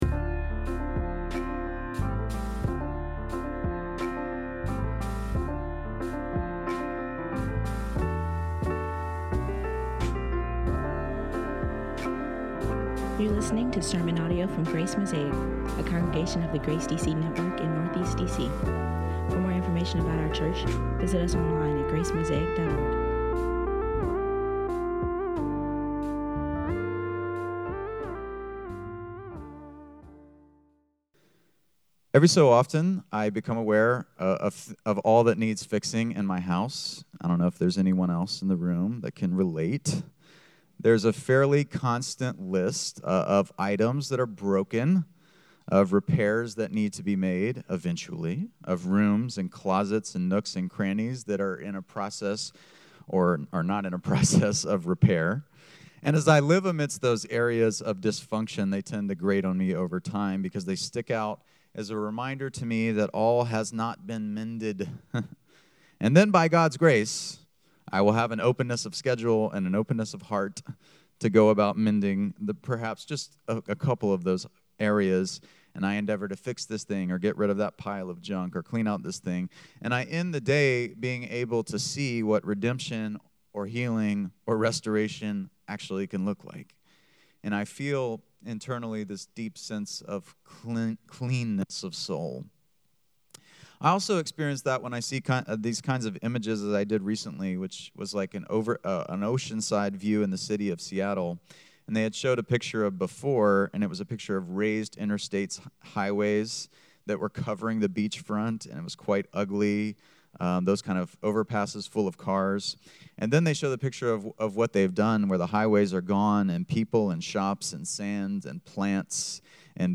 Ash Wednesday Homily